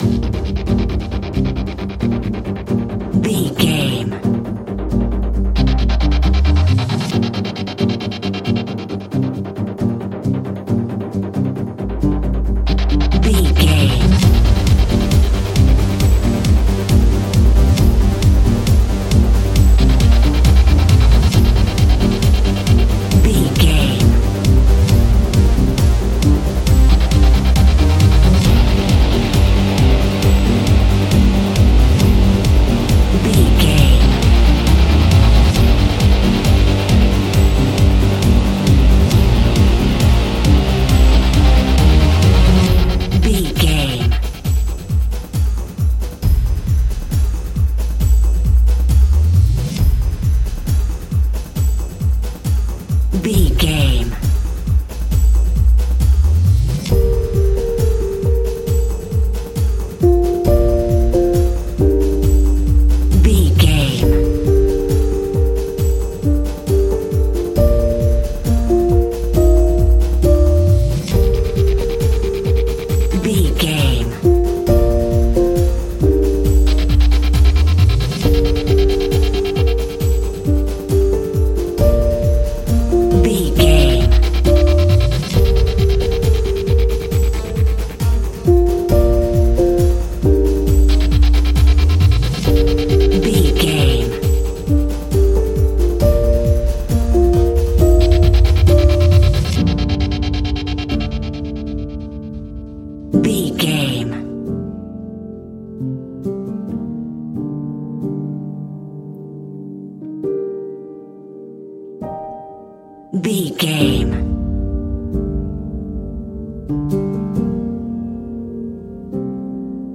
Aeolian/Minor
strings
percussion
synthesiser
brass
cello
double bass